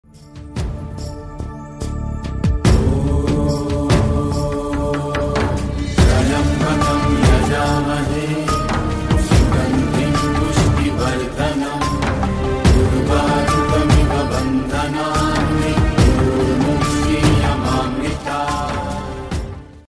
devotional ringtone
bhajan ringtone